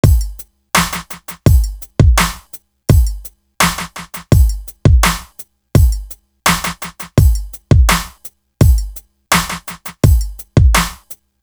Book Of Rhymes Drum.wav